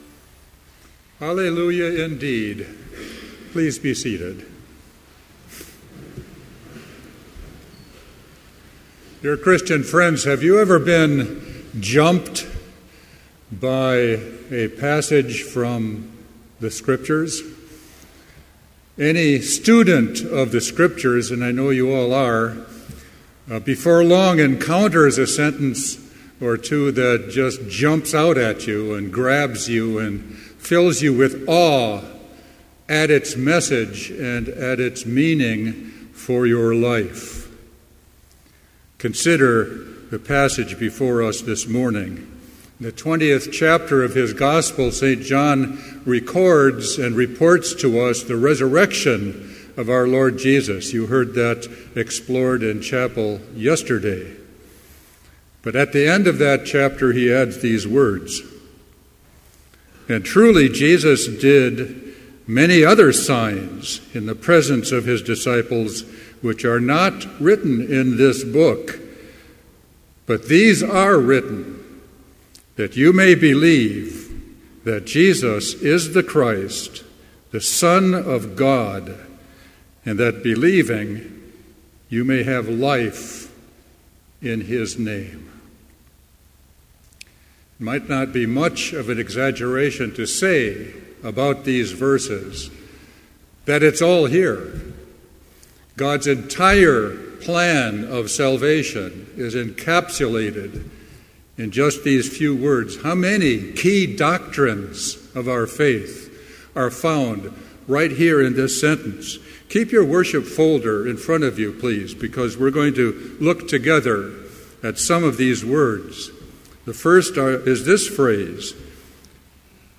Complete Service
This Chapel Service was held in Trinity Chapel at Bethany Lutheran College on Wednesday, April 15, 2015, at 10 a.m. Page and hymn numbers are from the Evangelical Lutheran Hymnary.